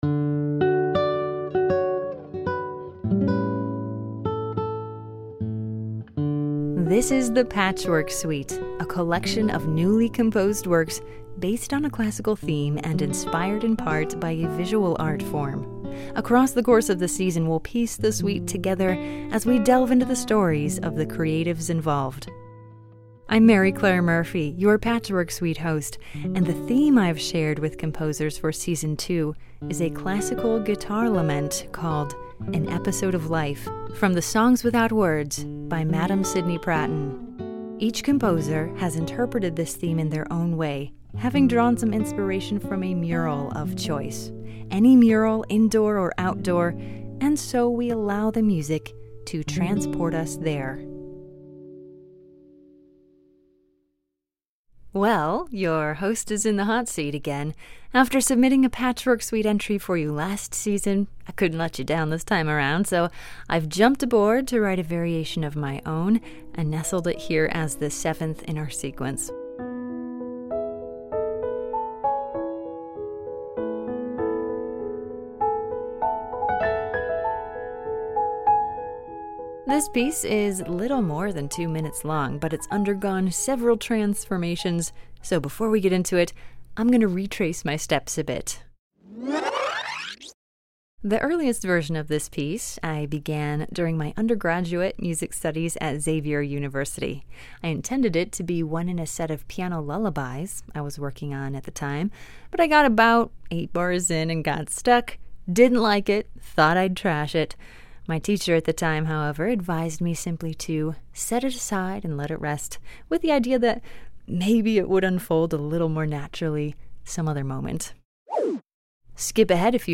song without words, first sketched in 2016 as a cradle song